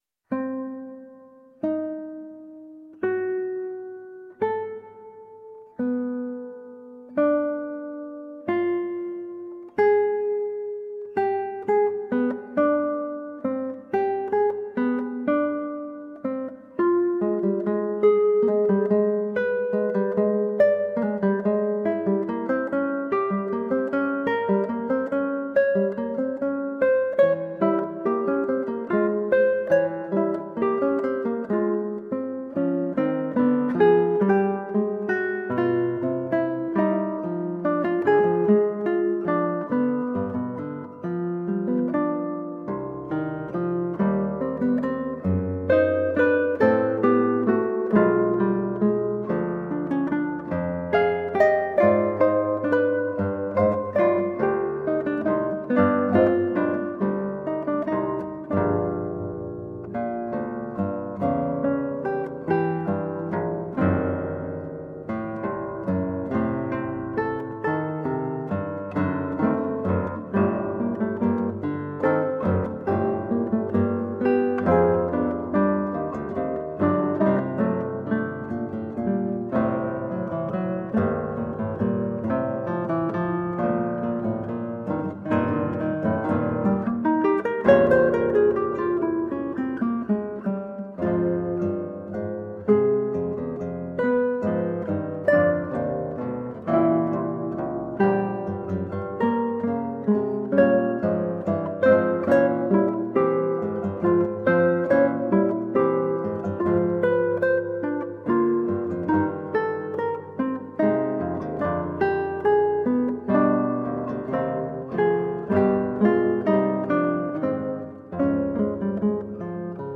Colorful classical guitar.
Classical Guitar